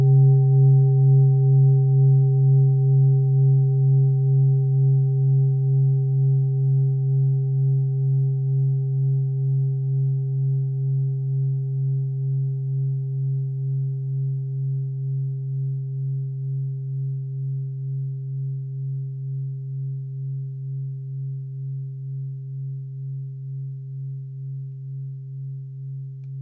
Klangschalen-Typ: Tibet
Klangschale Nr.5
Gewicht = 1190g
Durchmesser = 21,9cm
(Aufgenommen mit dem Filzklöppel/Gummischlegel)
klangschale-set-6-5.wav